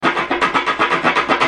Free MP3 funk music guitars loops & sounds 1
Guitare loop - funk 22